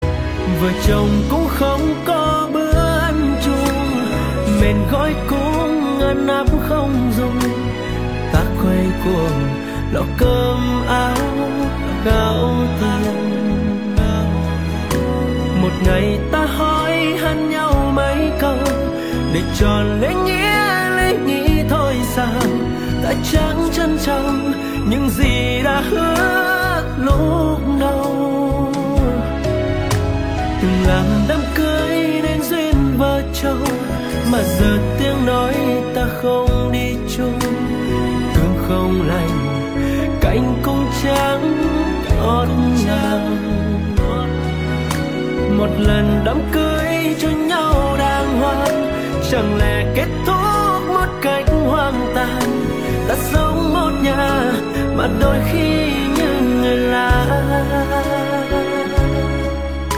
Nhạc Trữ Tình